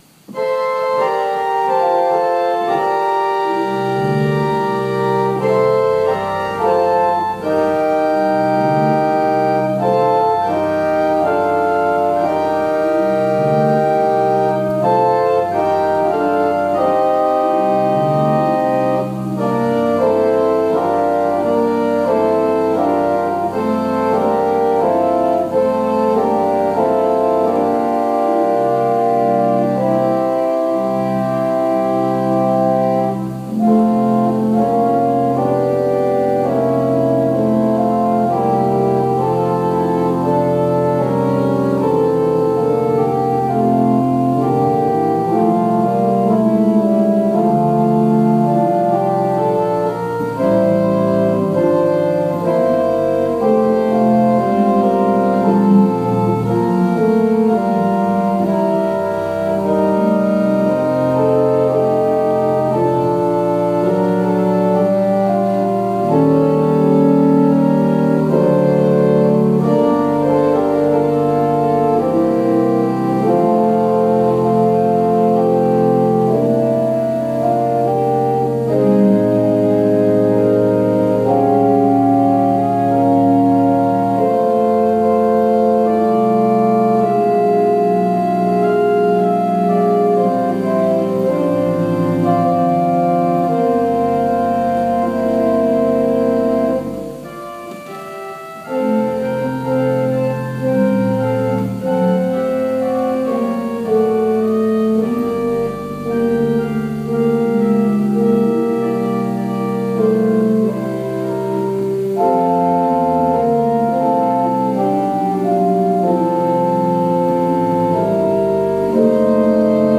Worship Service July 5, 2020 | First Baptist Church, Malden, Massachusetts
Sermon